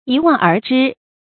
一望而知 注音： ㄧ ㄨㄤˋ ㄦˊ ㄓㄧ 讀音讀法： 意思解釋： 一看就明白。